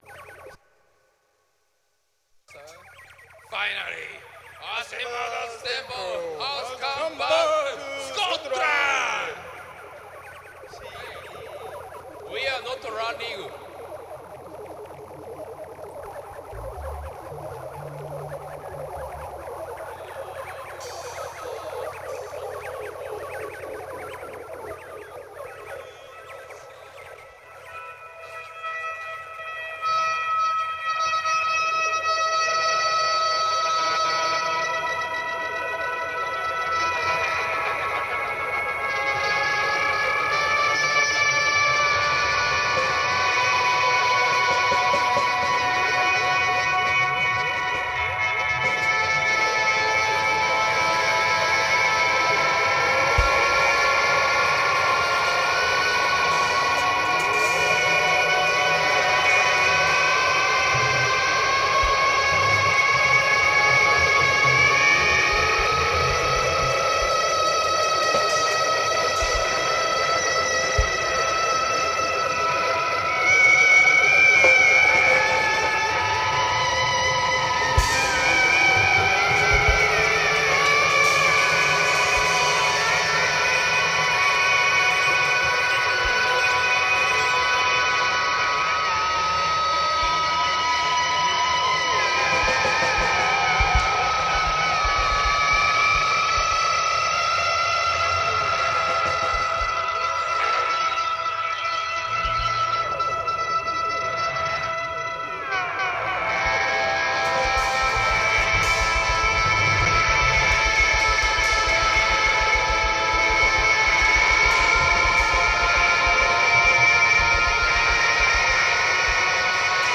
psychedelic majesty
A freak-out group for the 21st century, burn